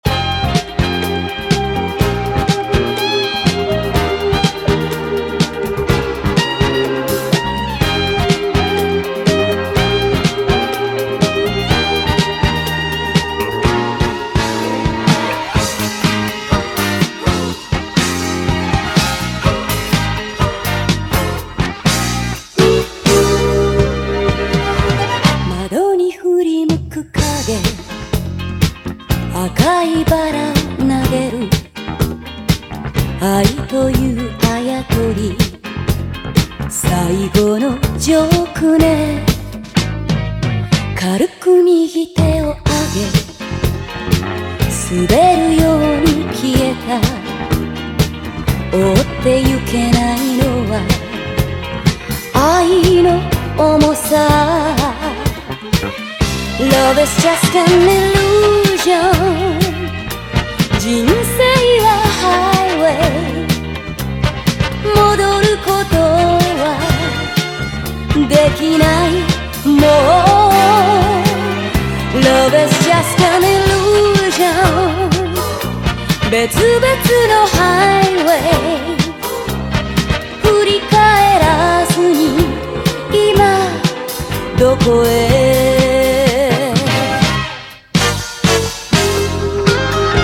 JAPANESE GROOVE